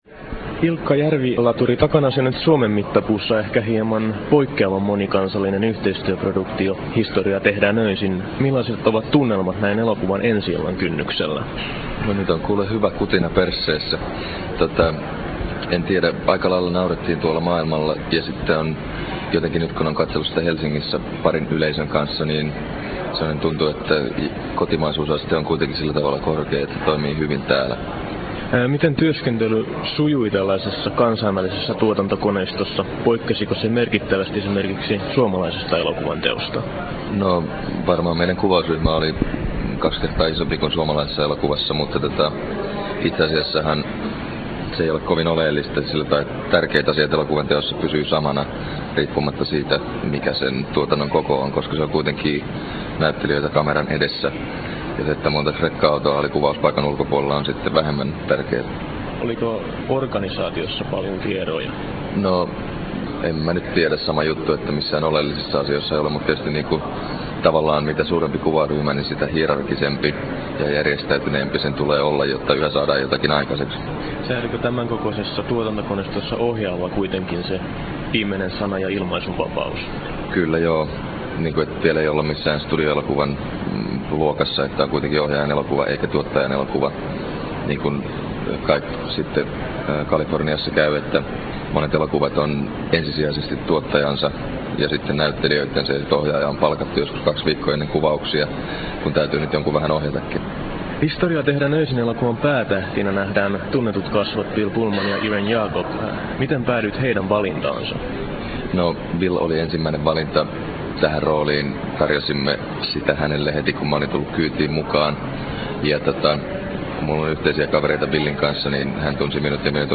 Nauhoitettu Turussa